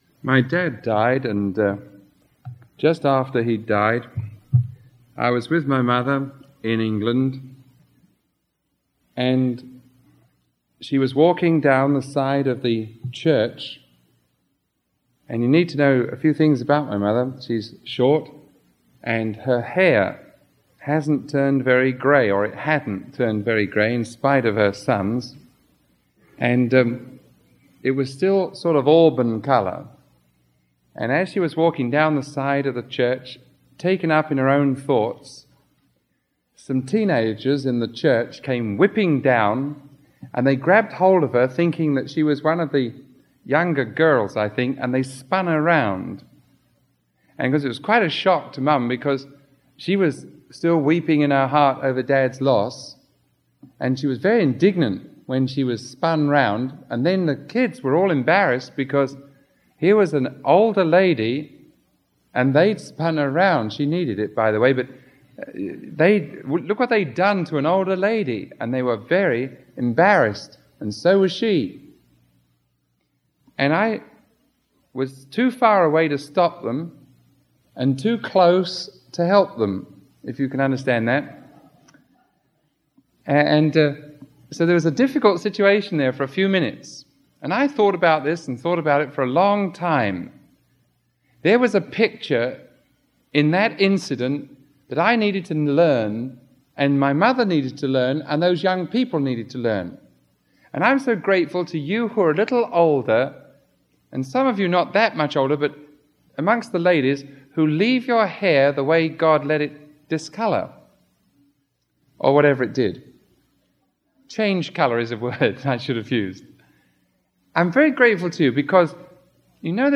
Sermon 0007B recorded on November 26